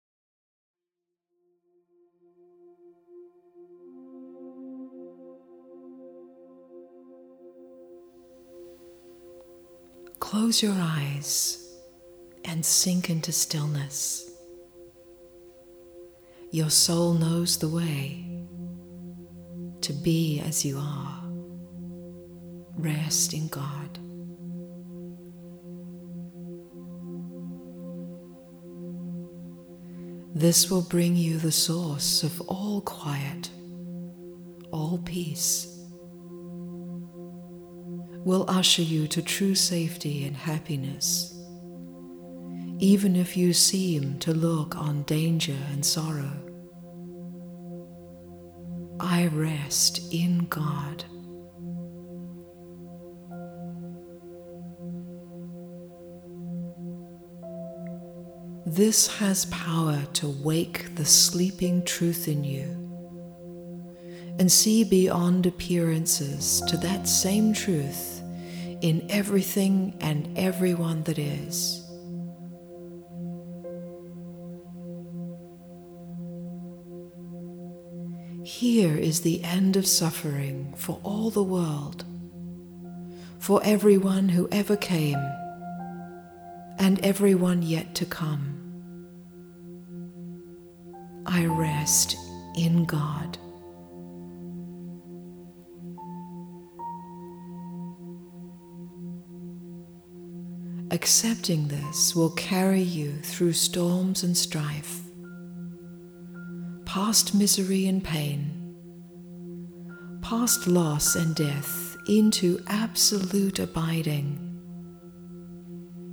Home / Shop / Audio Meditations / Be As You Are Be As You Are Rest in the shimmering emptiness that is the source of this world, and let go into who you are.
Harmonium and vocal